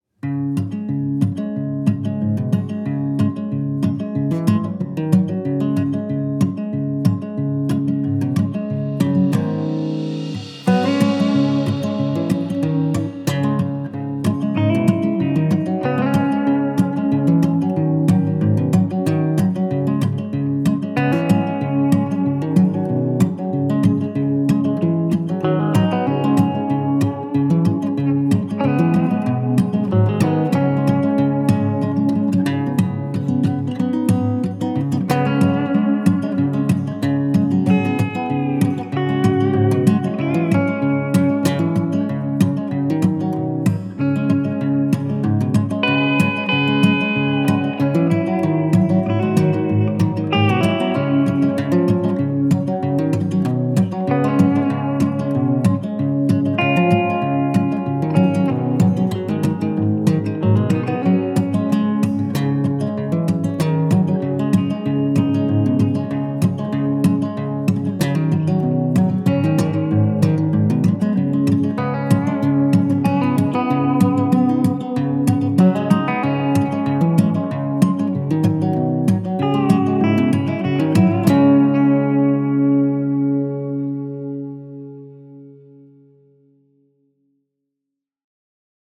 Un flow BPM lascif, envoûtant et liquoreux.
Mezzo forte (mf) - Deep vibes – 80 BPM La Musique https